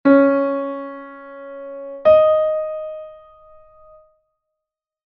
intervalocompuesto1.mp3